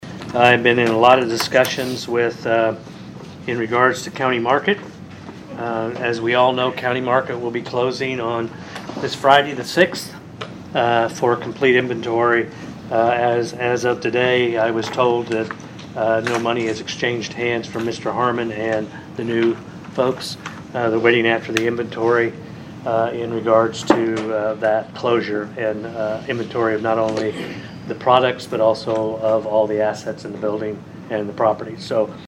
During Monday’s Vandalia City Council meeting, Mayor Rick Gottman further discussed the situation and what may happen going forward.